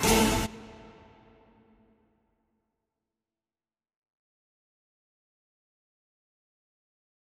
MDMV3 - Hit 17.wav